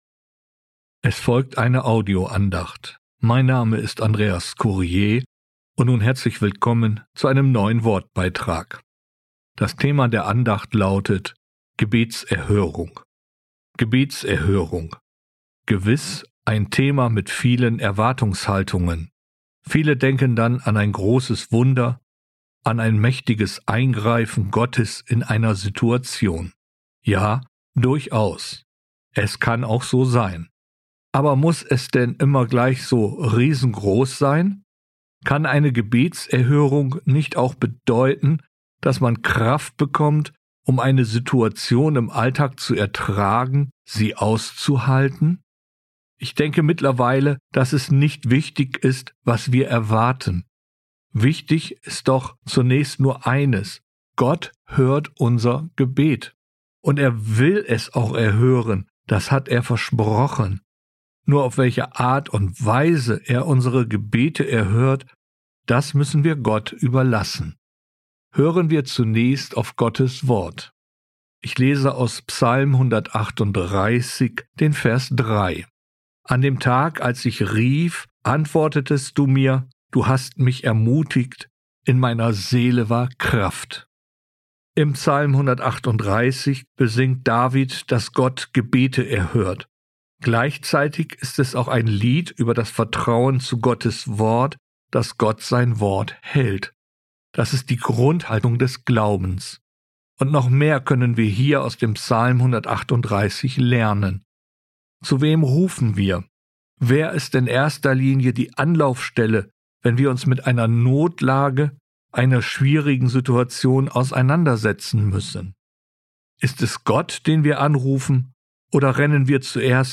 Gebetserhörung, eine Audioandacht